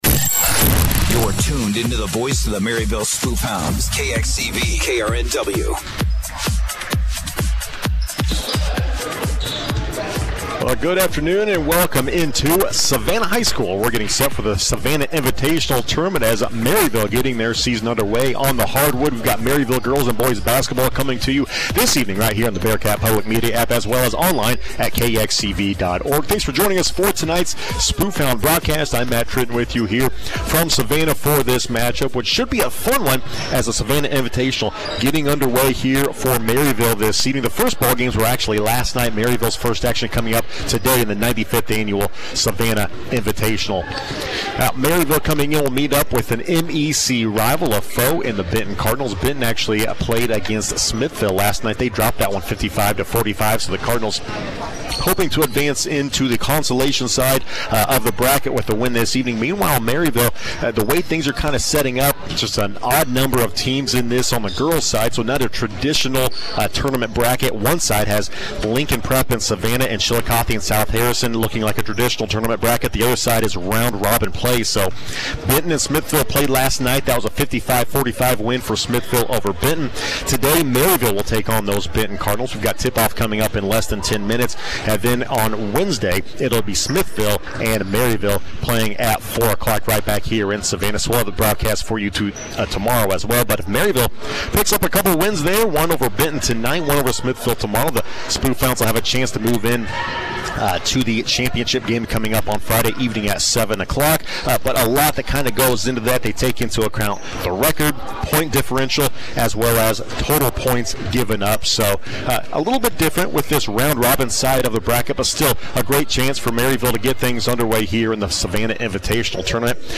Nov. 28, 2023Game | Spoofhound Basketball